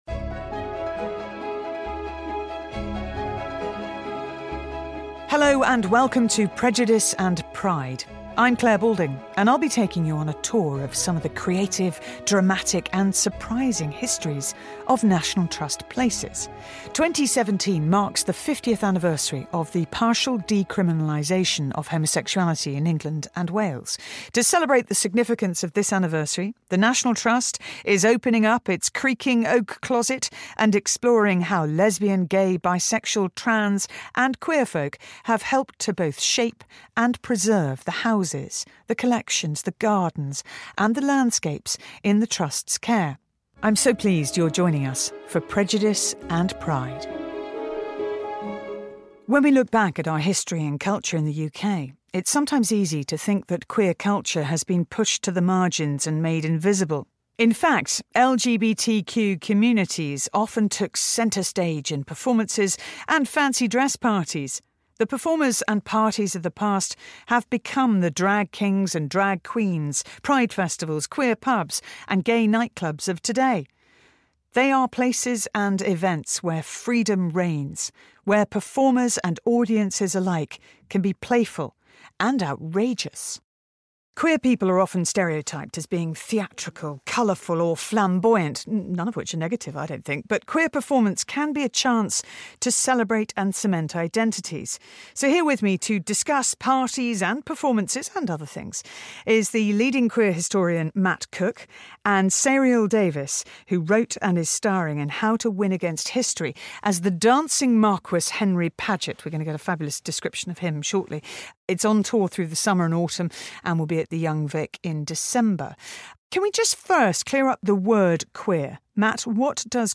Reading by Cyril Nri.